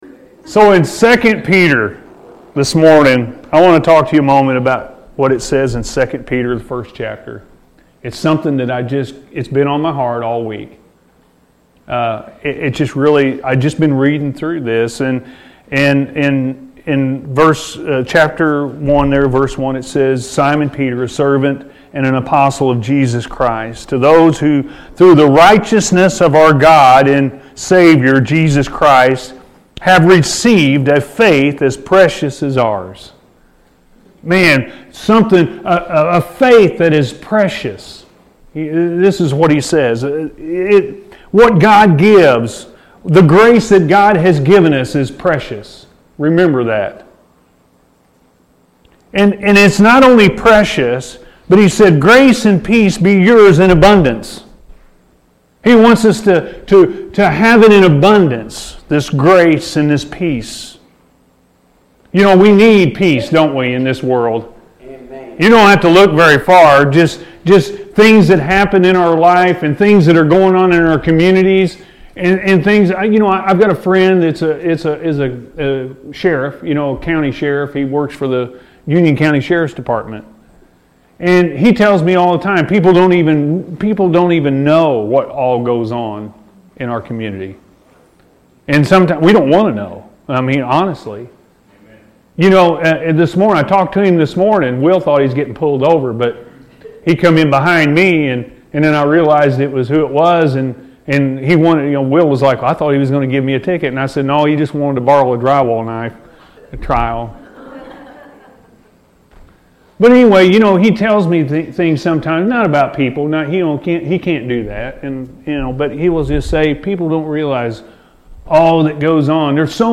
Grace That God Gives Is Precious-A.M. Service